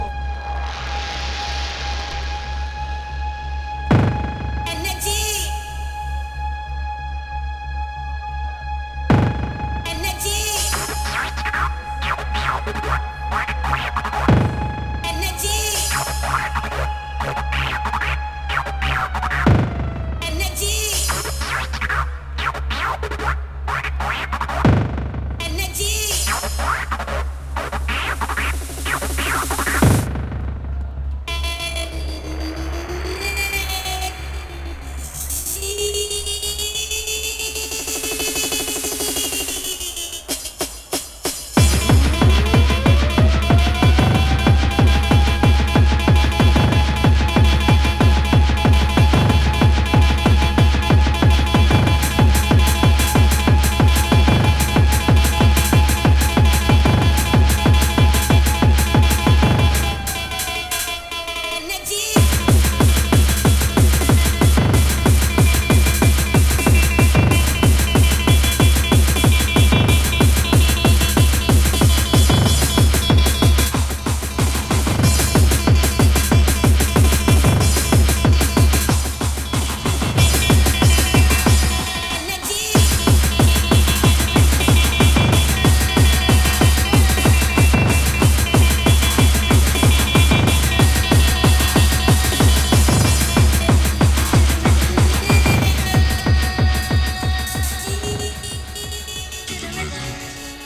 Genre: Hardcore.